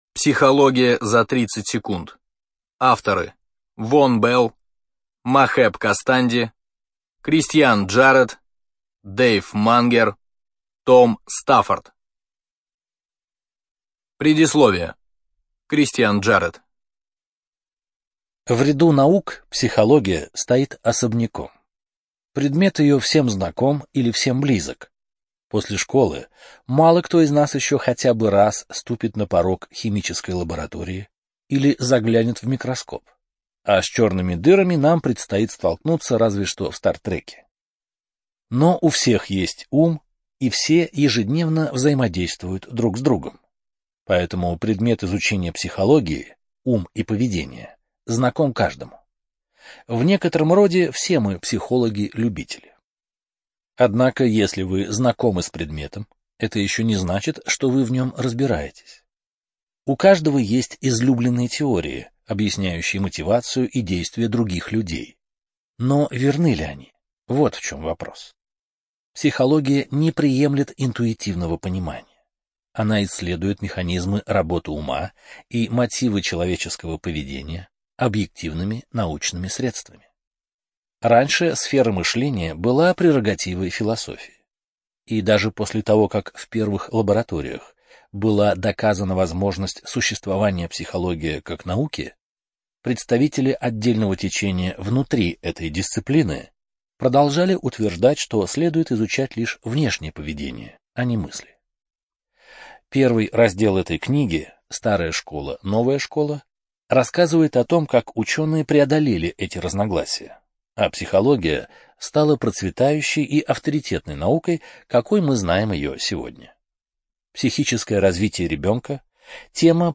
ЖанрНаучно-популярная литература, Психология